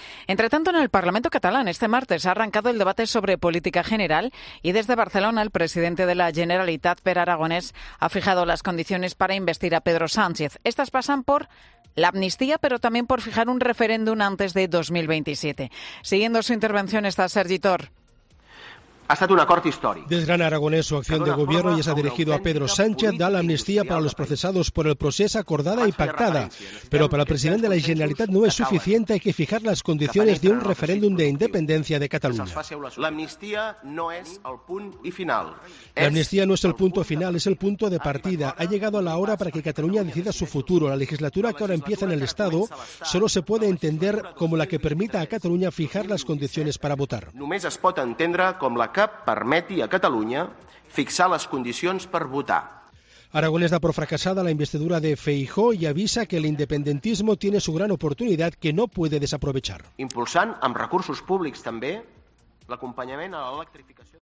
Con estas palabras se ha dirigido Pere Aragonés al presidente del Gobierno de España en funciones, Pedro Sánchez en el arranque de su discurso durante el Debate de Política General en el Parlamento catalán.